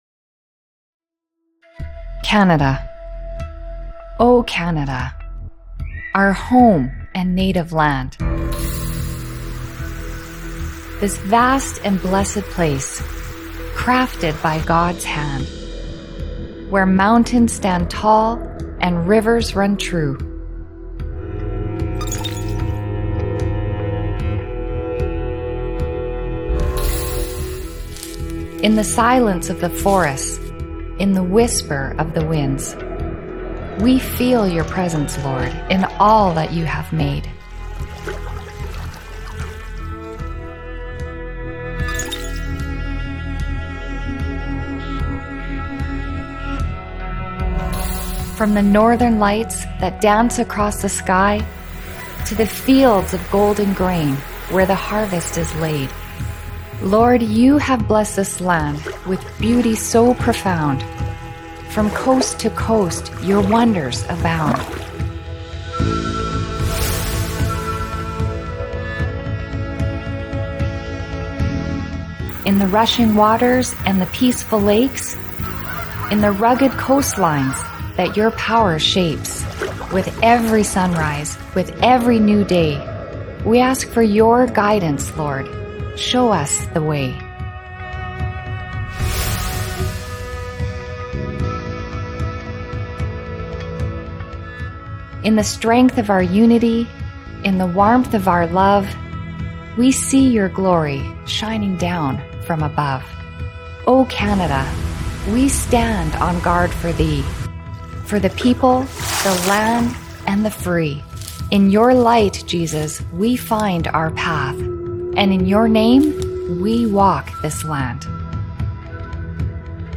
Episode recorded at Lacombe Lake, AB.